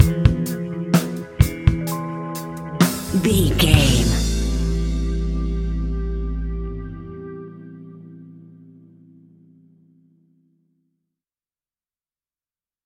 Epic / Action
Fast paced
In-crescendo
Uplifting
Ionian/Major
A♭
hip hop